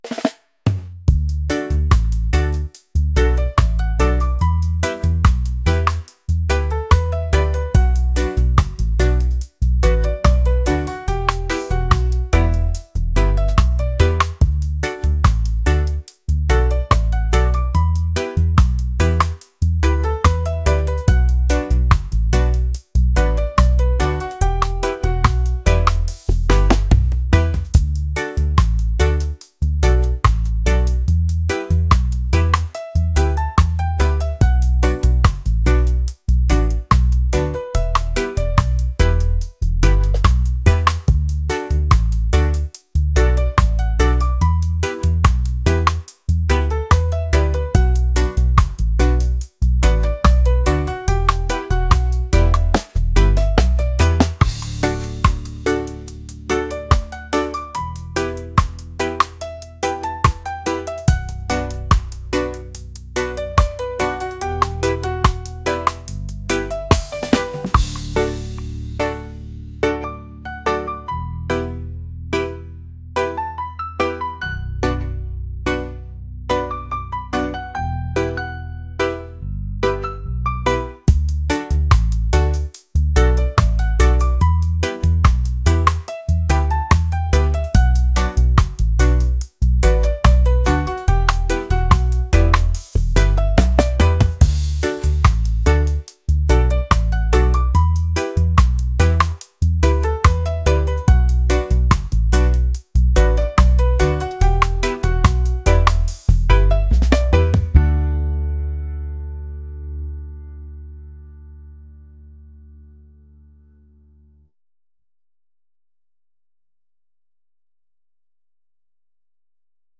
reggae | soul & rnb | funk